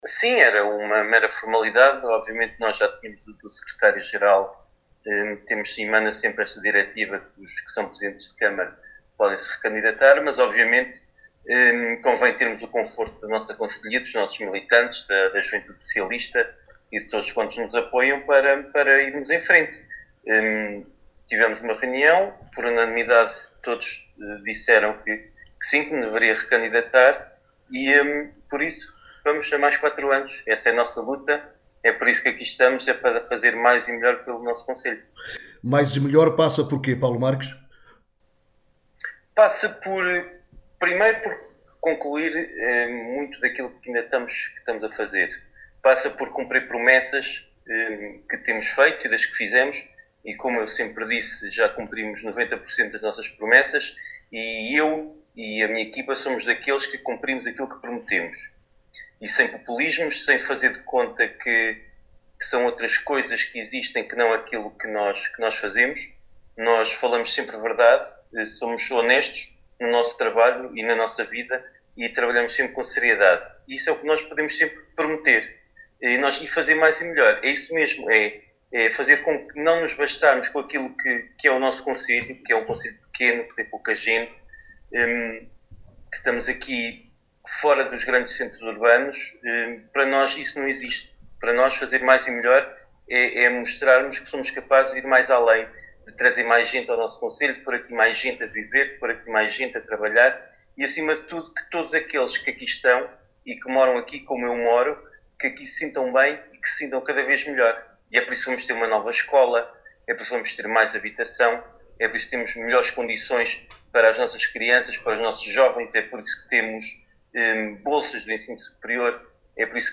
Paulo Marques, em declarações à Alive FM, fala da sua recandidatura e das prioridades “fazer mais e melhor pelo nosso concelho…”.